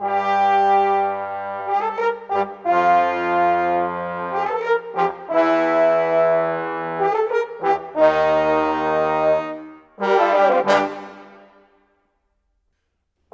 Rock-Pop 07 Brass 01.wav